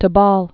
(tə-bôl)